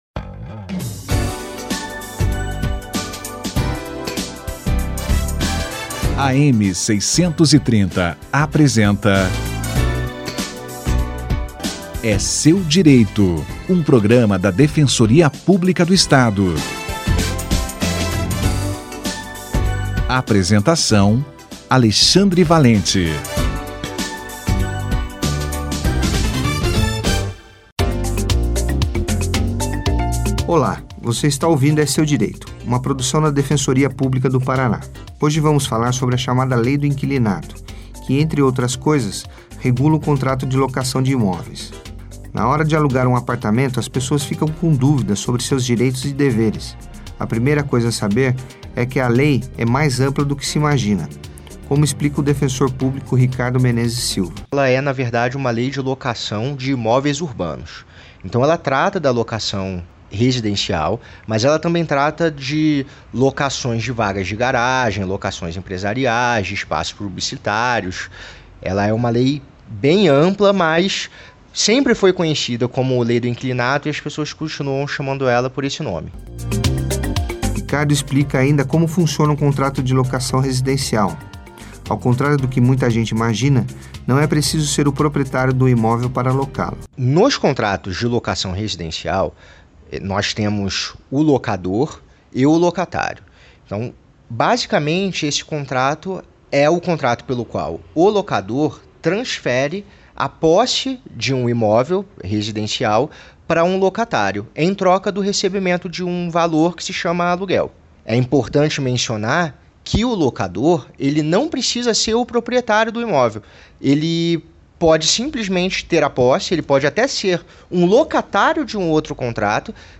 Lei do Inquilinato - Entrevista